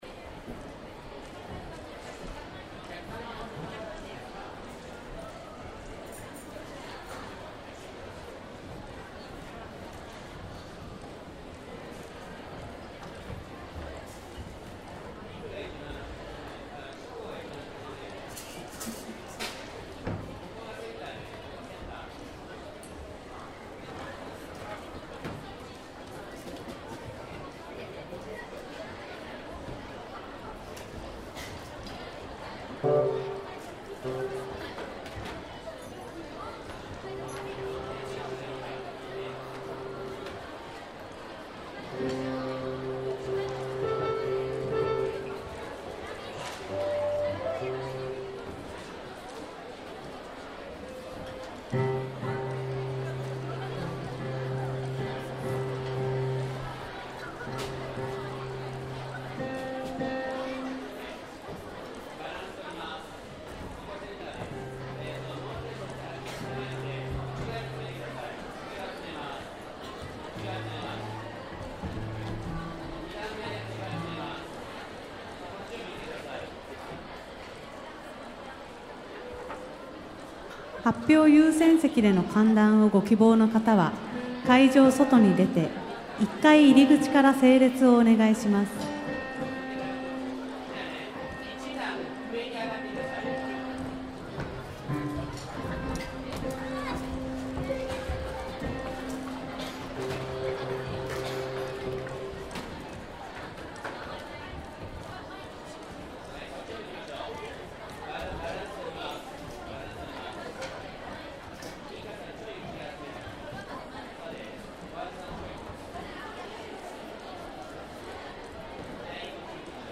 ↓ click 令和7年11月６日 昭島市小学校音楽会 ６年生【音声 】 武蔵野小では を推進しています。